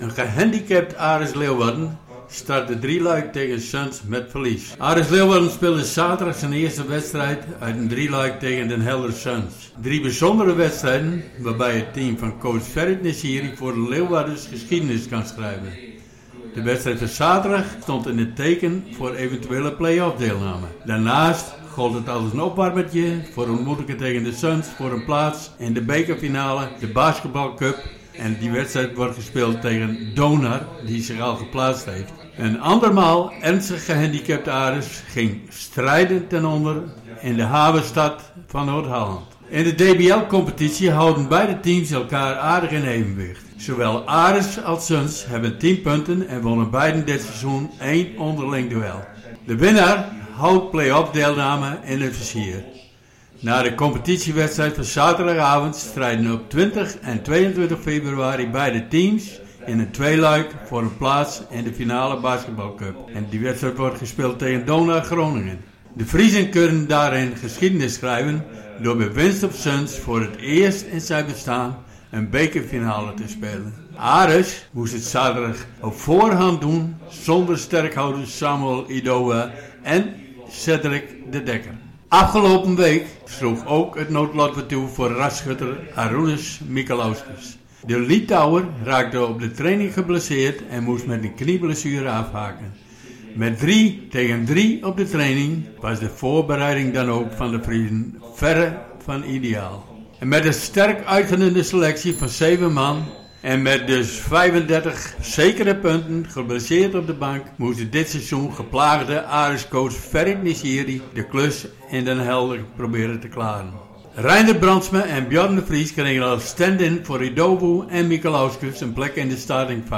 Wedstrijd verslag Den Helder Suns tegen Aris Leeuwarden